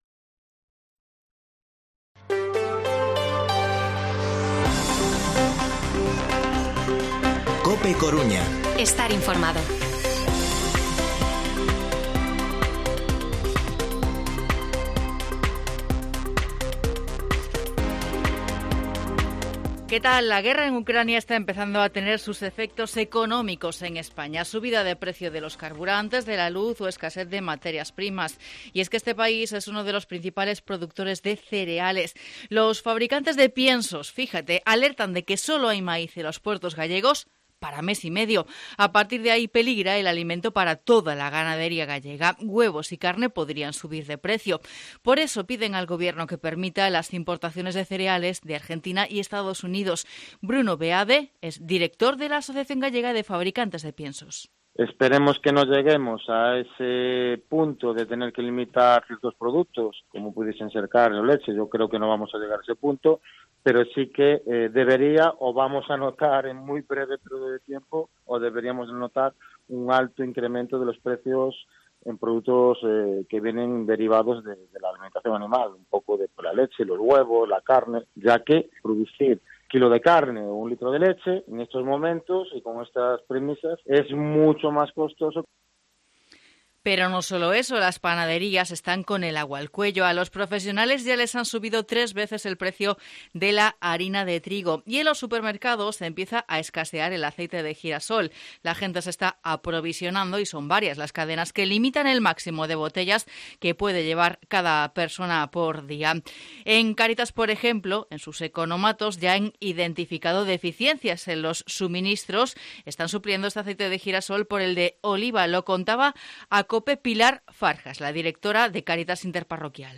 Informativo Mediodía COPE Coruña lunes, 7 de marzo de 2022 14:20-14:30